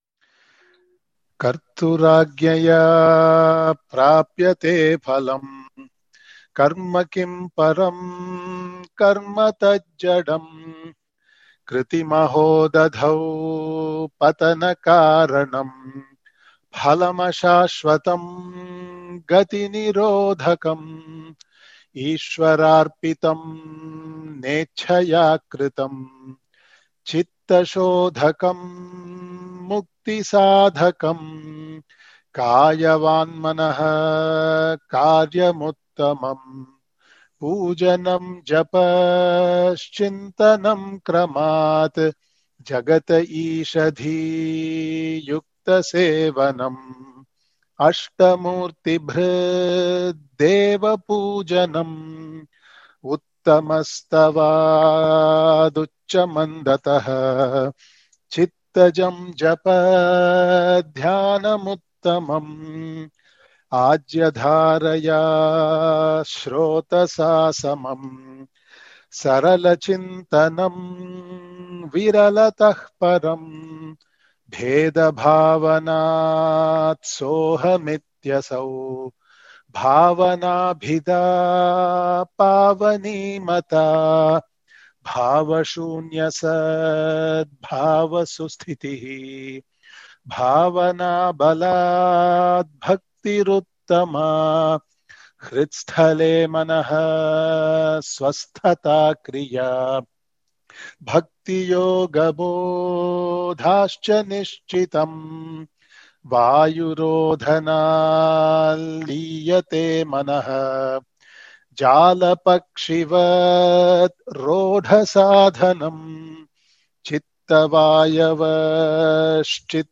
mp3 audio print link recitation Tamiḷ recitation pdf